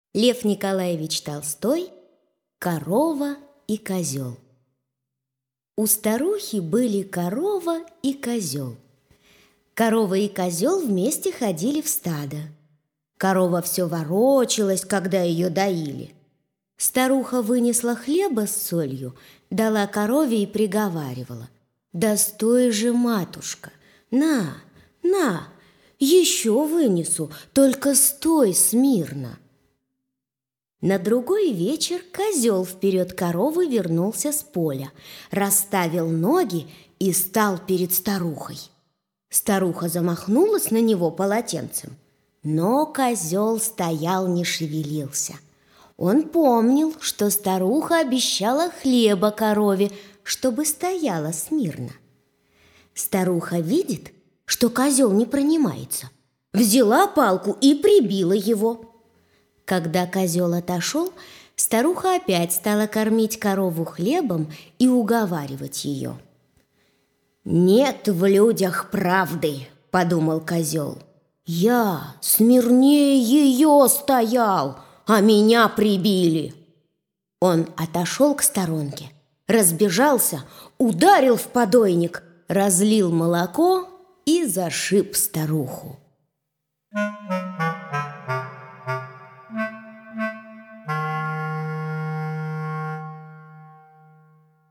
Корова и козёл - аудиосказка Льва Толстого - слушать онлайн | Мишкины книжки